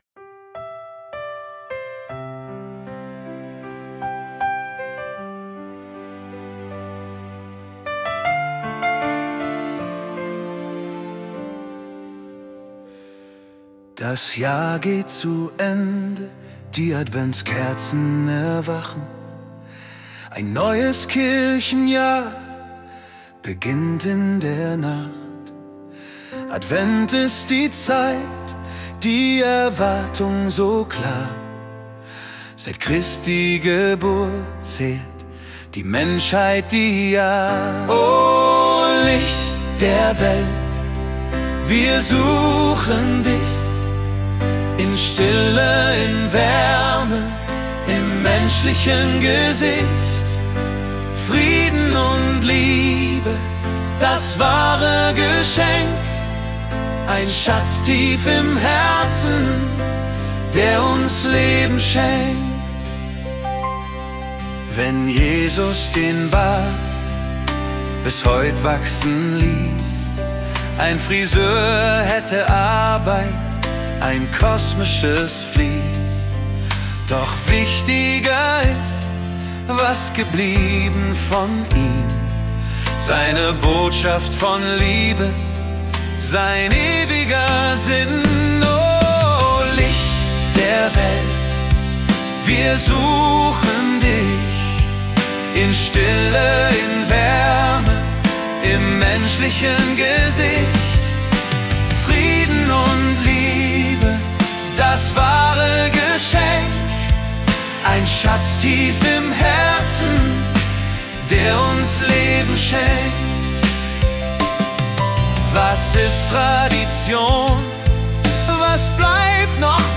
Song als Musik-Datei.